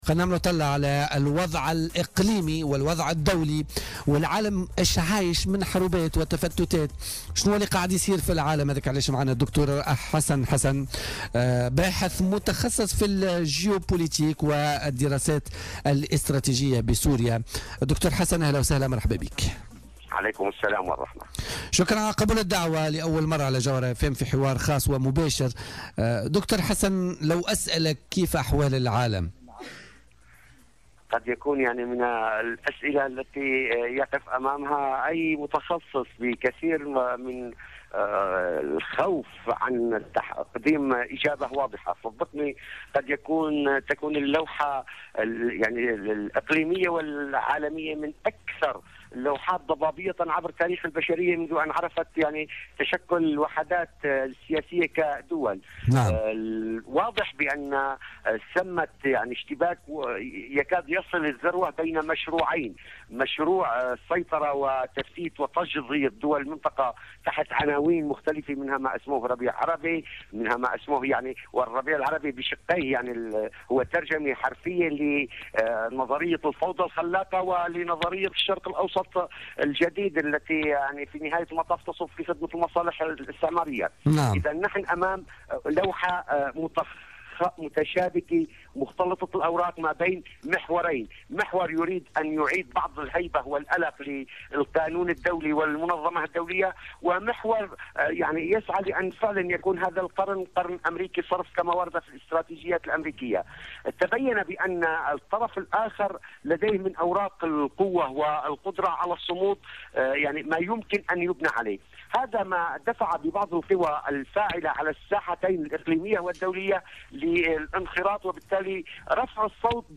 واعتبر الخبير العسكري خلال مداخلة هاتفية له في برنامج "بوليتيكا" اليوم الجمعة، الربيع العربي ترجمة حرفية للفوضى الخلاقة ولنظرية الشرق الأوسط الجديد التي تصب في صالح القوى الاستعمارية. وأضاف الخبير أن الصراع الكبير الآن يدور بين القوتين الفاعلتين في العالم، وهما روسيا وأمريكا، وأن سوريا محور المقاومة.